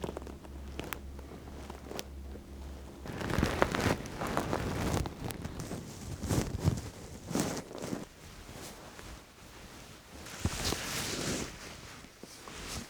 To build a general deRustle algorithm, we were going to have to record our own collection of isolated rustle.
rustle_training.wav